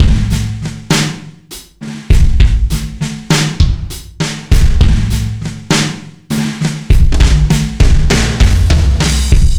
jazz drunk 100bpm 02.wav